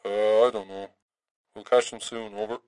Tag: 喋喋不休 警察 收音机